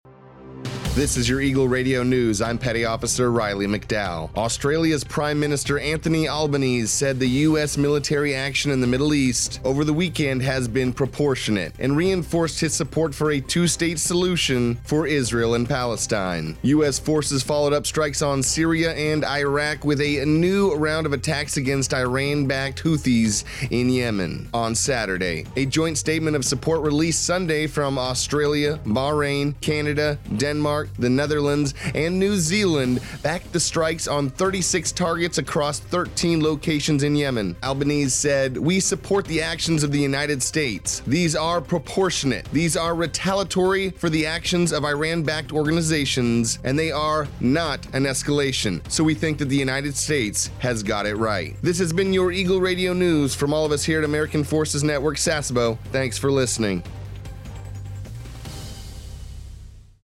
A TFNewscast for AFN Sasebo's radio about Australia’s Prime Minister Anthony Albanese who said the U.S. military action in the Middle East over the weekend was proportionate, and reinforced his support for a two-state solution for Israel and Palestine.